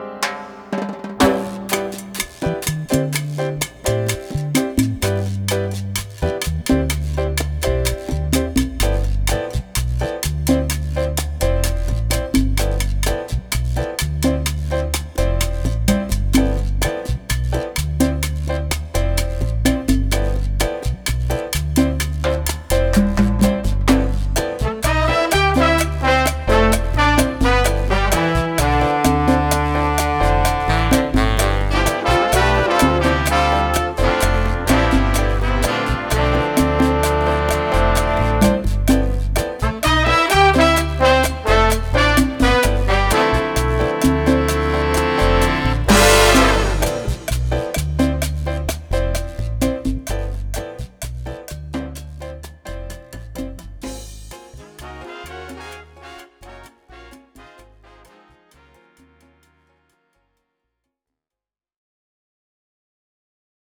Here are some tracks from the studio that we hope you enjoy.